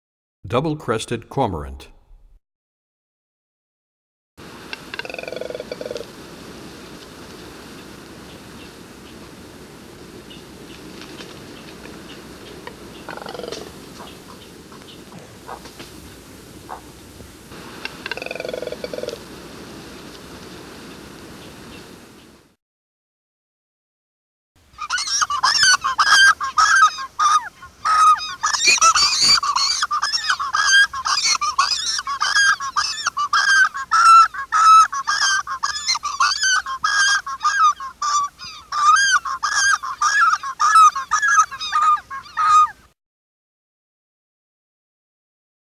31 Double Crested Cormorant.mp3